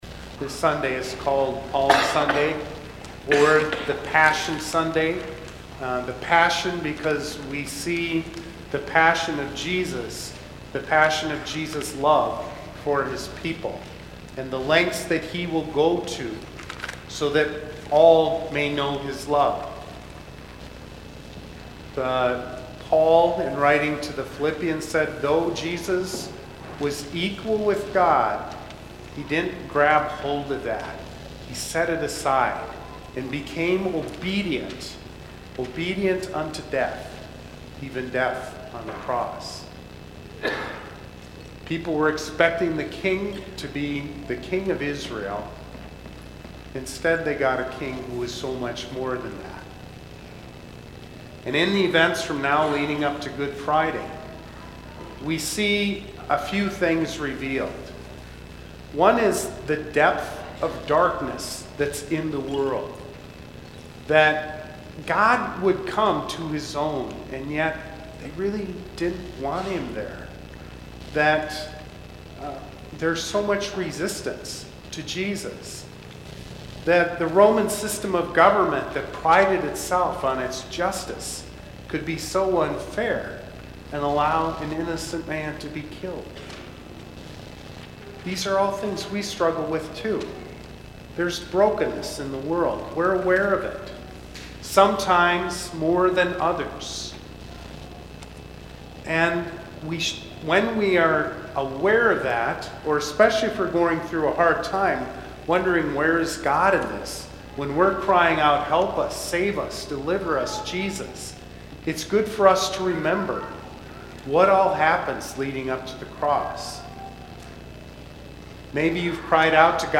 CLC 2013-03-24 Sermon audio only